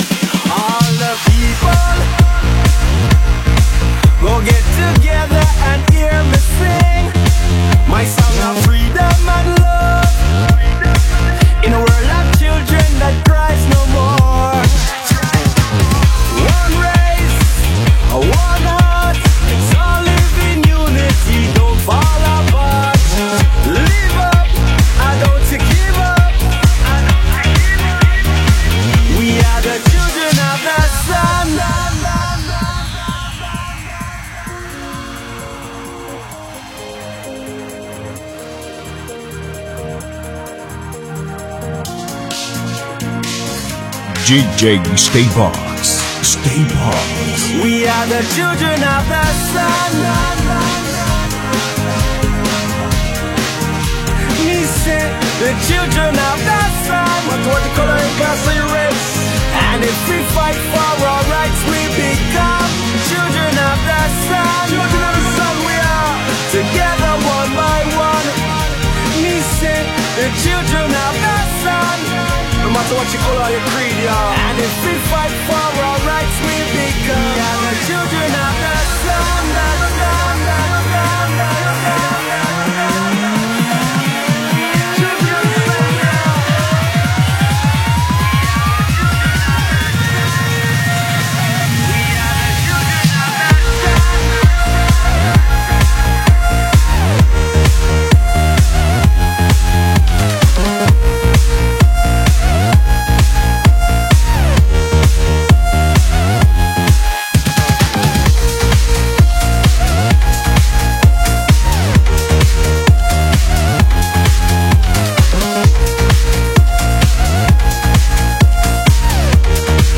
Vem ouvir essa seleção com muito electrohouse